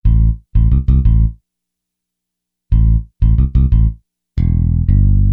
Hiphop music bass loop - 90bpm 71